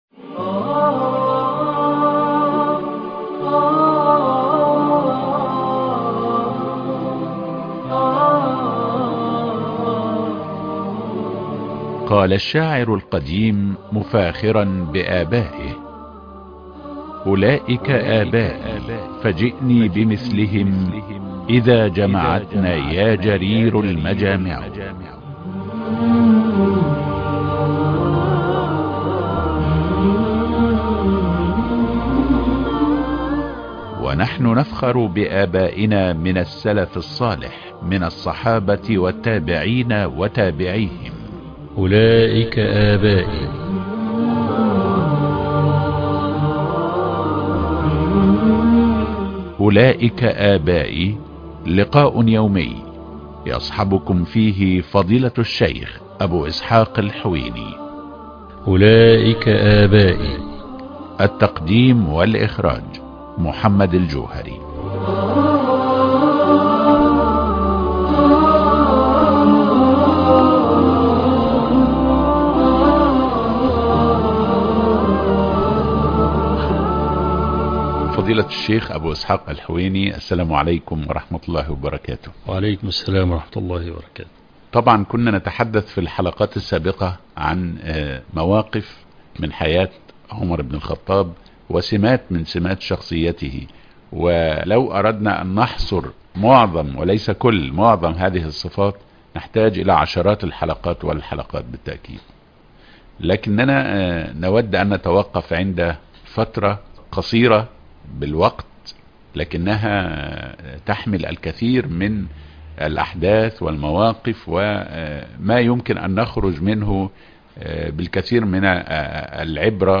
مواعظ في مقتل عمر بن الخطاب ح20 أولئك أبائي - الشيخ أبو إسحاق الحويني